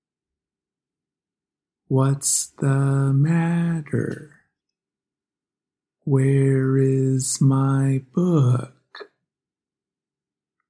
There is a common pattern in English where the tones go normal-high-low.
This is called Rising-Falling intonation.